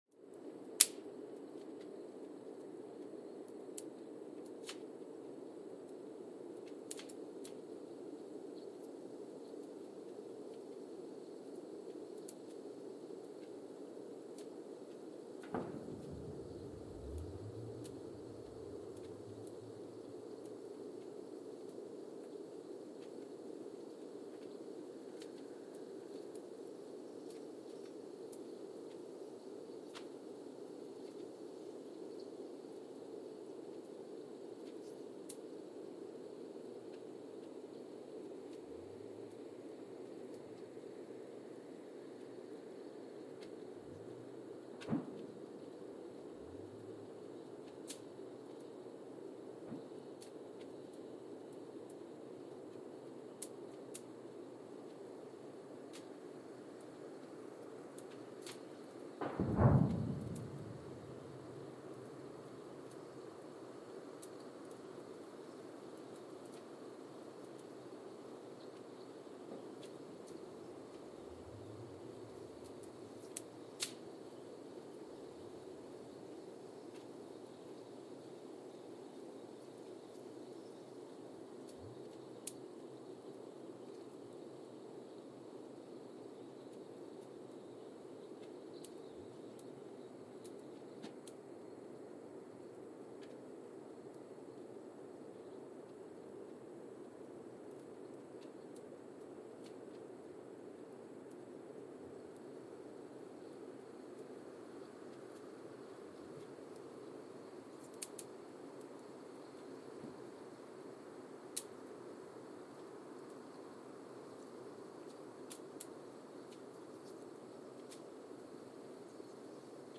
Mbarara, Uganda (seismic) archived on October 24, 2021
No events.
Sensor : Geotech KS54000 triaxial broadband borehole seismometer
Speedup : ×1,800 (transposed up about 11 octaves)
Loop duration (audio) : 05:36 (stereo)